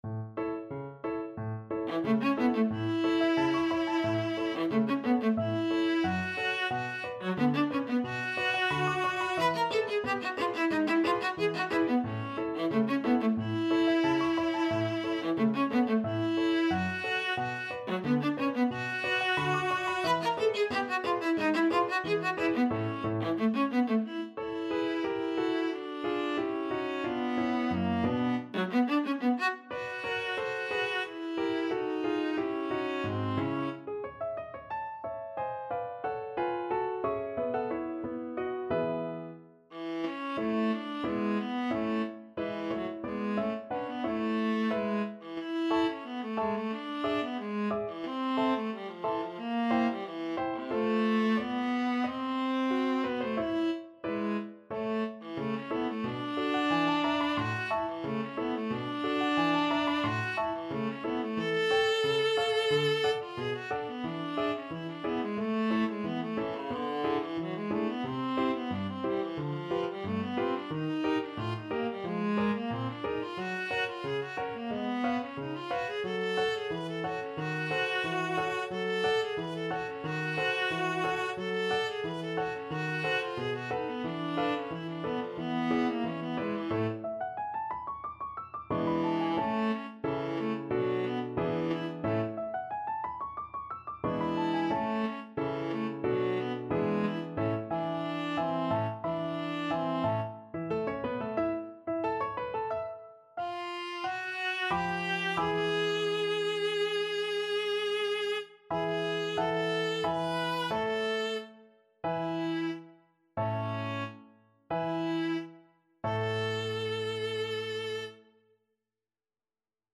4/4 (View more 4/4 Music)
Allegro Moderato [ = c.90] (View more music marked Allegro)
Classical (View more Classical Viola Music)